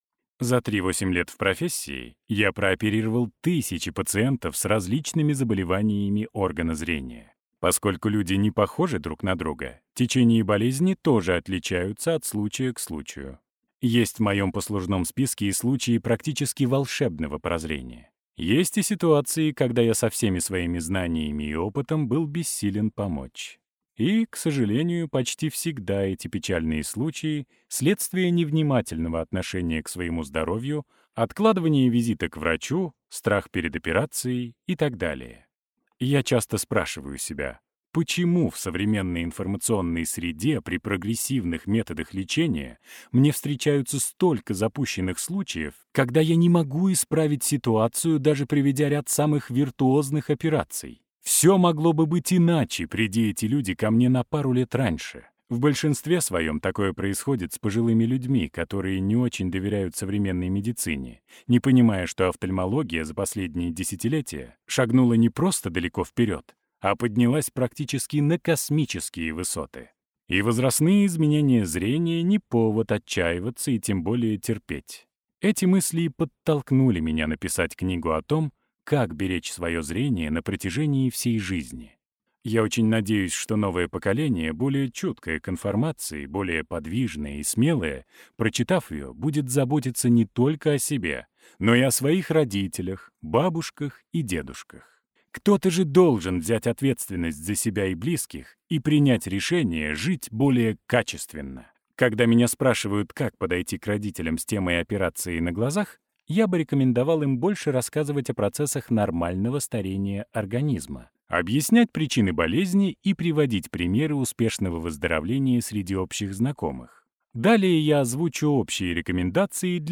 Аудиокнига Во все глаза. Секретная книга для тех, кто хочет сохранить или исправить зрение | Библиотека аудиокниг